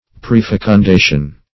Search Result for " prefecundation" : The Collaborative International Dictionary of English v.0.48: Prefecundation \Pre*fec`un*da"tion\, n. (Physiol.)
prefecundation.mp3